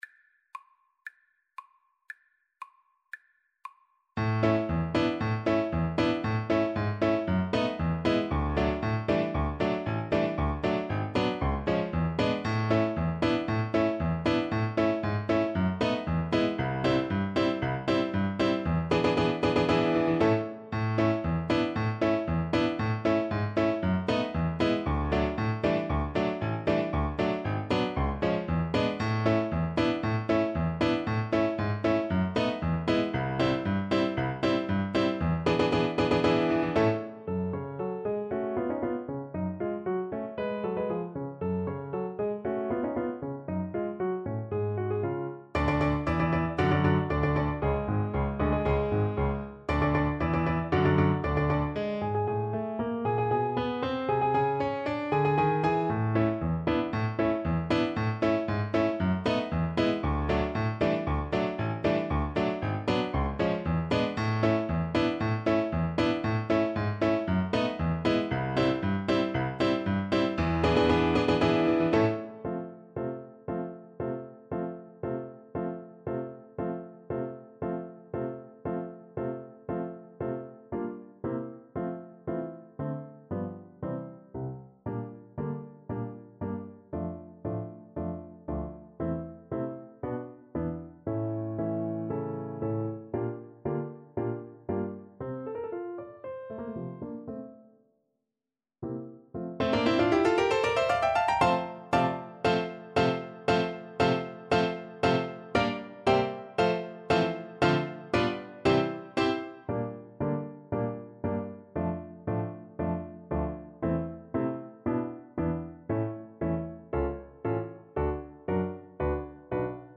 Play (or use space bar on your keyboard) Pause Music Playalong - Piano Accompaniment Playalong Band Accompaniment not yet available transpose reset tempo print settings full screen
A major (Sounding Pitch) (View more A major Music for Violin )
Allegro giocoso =116 (View more music marked Allegro giocoso)
Classical (View more Classical Violin Music)